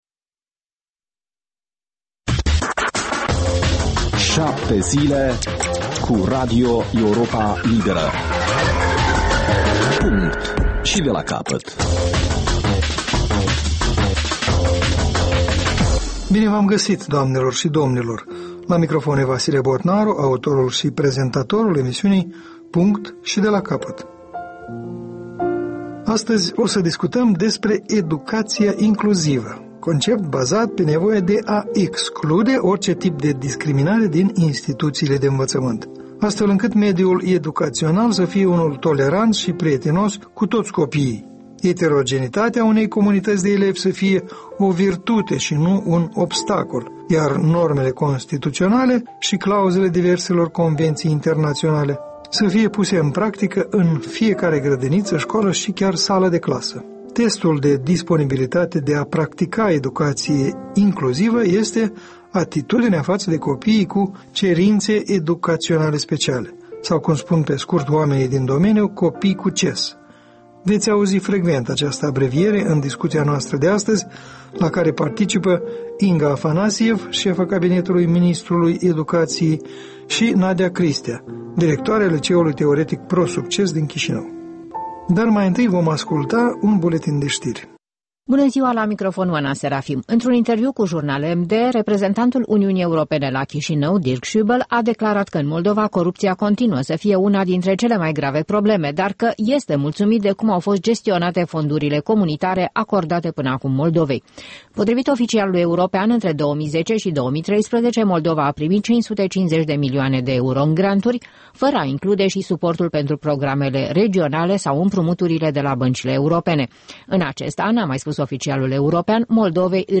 O discuţie la masa rotundă